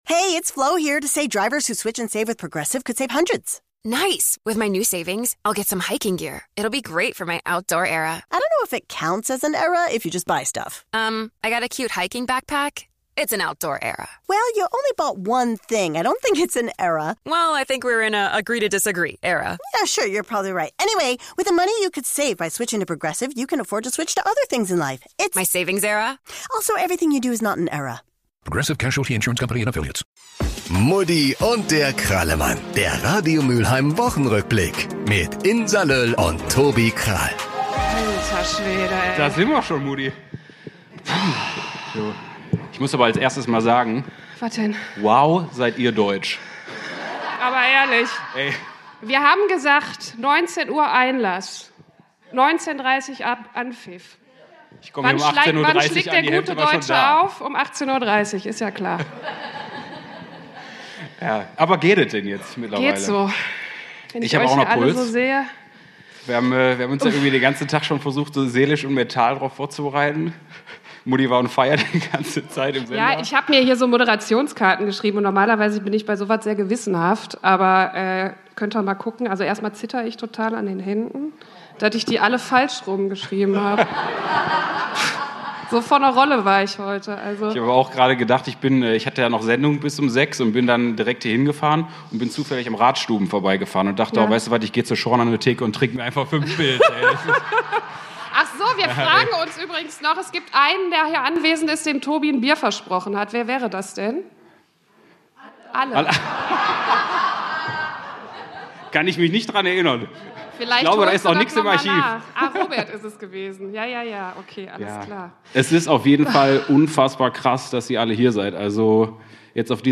Das war eine gelungene Premiere!